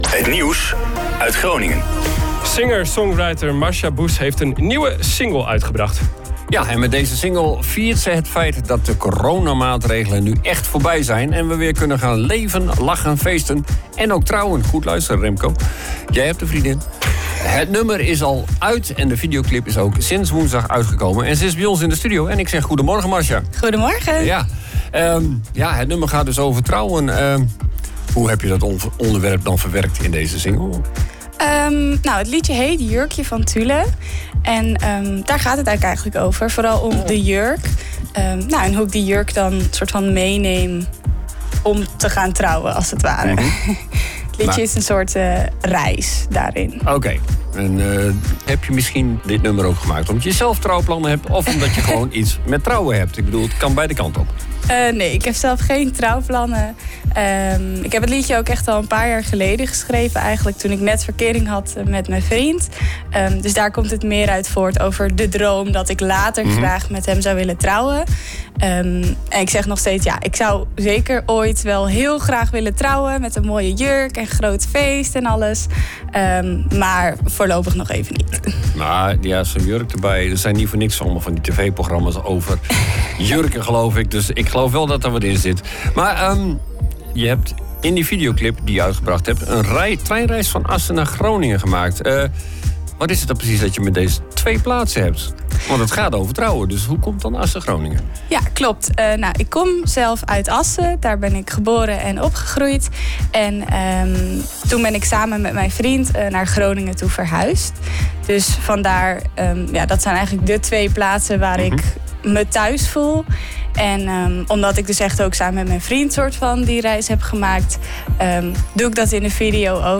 zo vertelde ze in een interview bij de OOG Ochtendshow.